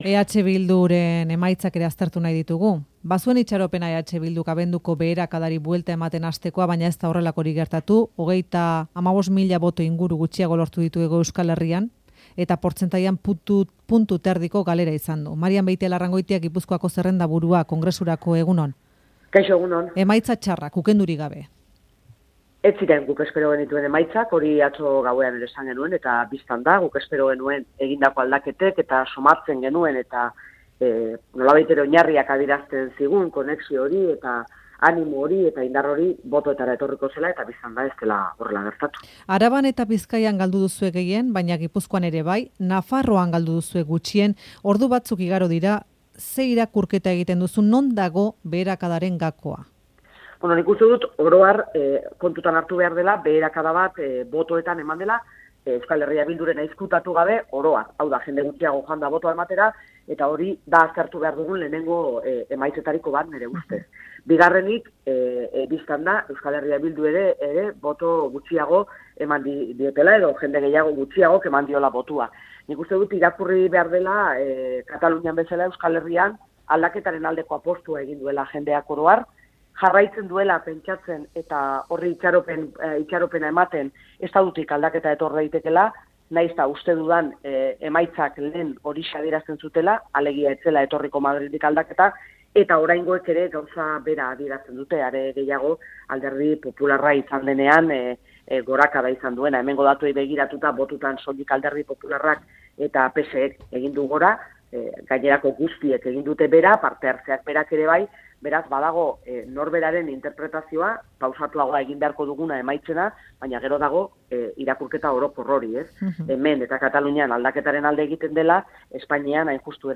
Marian Beitialarrangoitia, ekainaren 26ko hauteskundeen irakurketa, Faktorian, Euskadi Irratian.